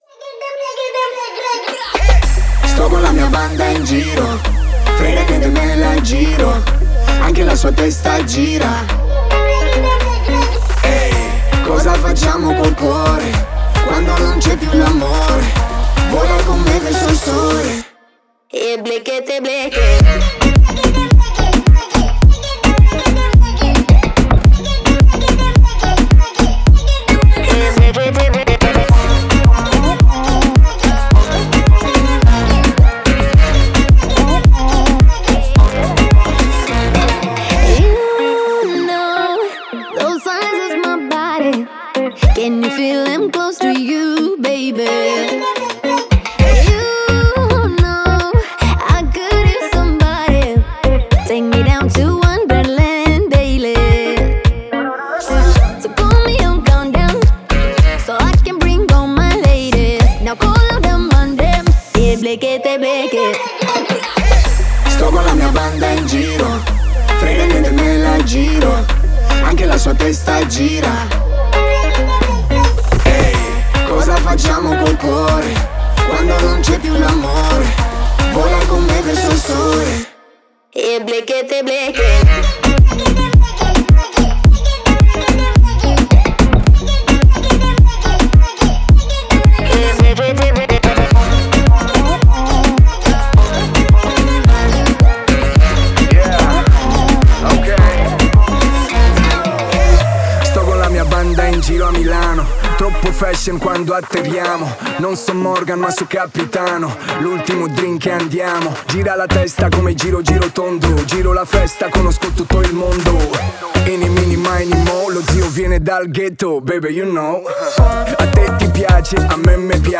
это зажигательный трек в жанре латин-поп и EDM